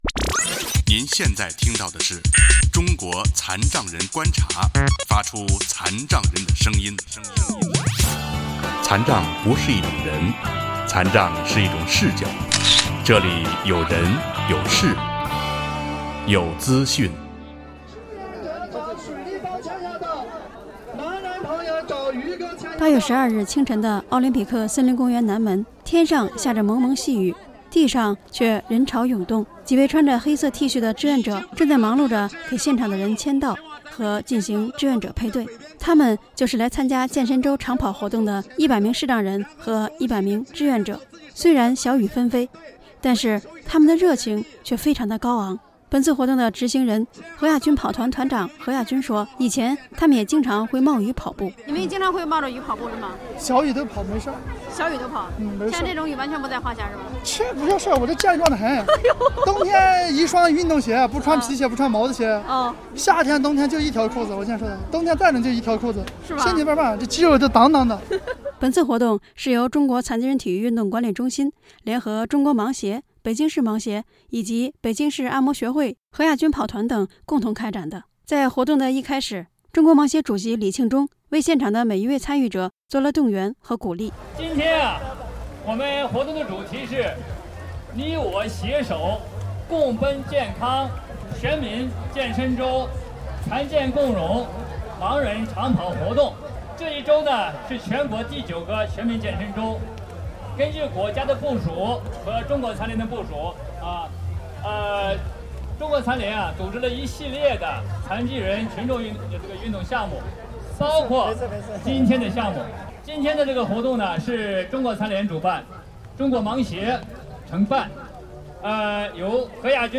以下是《有资讯》栏目组对此次长跑活动的采访：